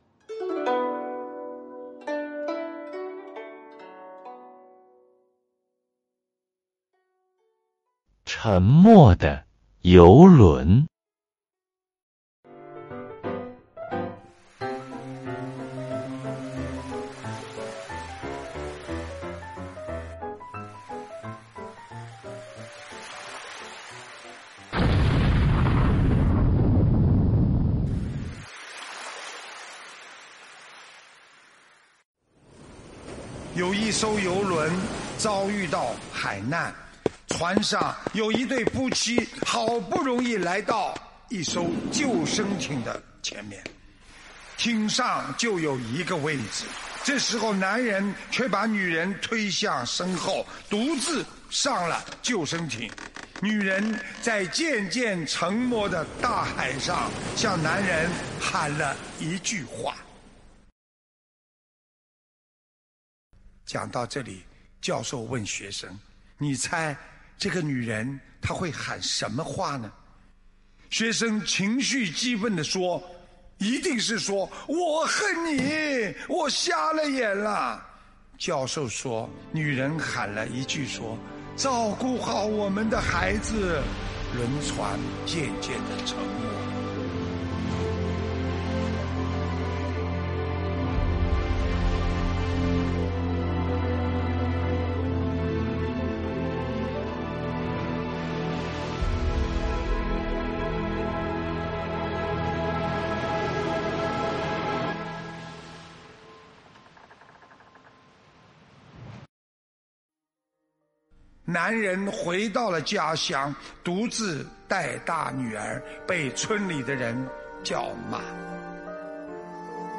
音频：《沉没的游轮》师父讲故事！摘自_悉尼法会开示2019年01月27日！